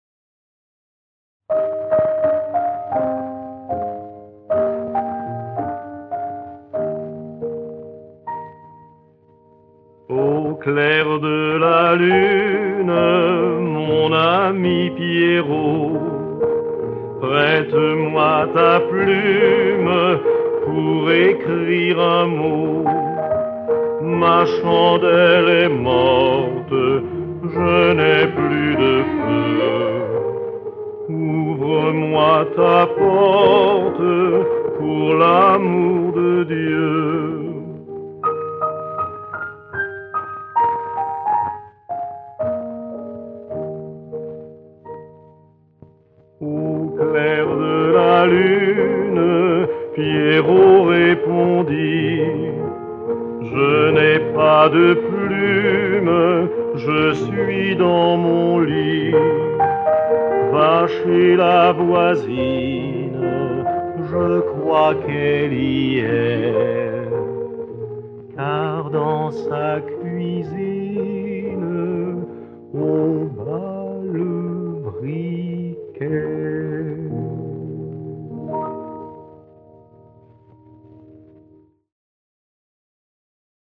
chansons pour enfants